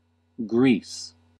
Greece /ˈɡrs/
En-us-Greece.ogg.mp3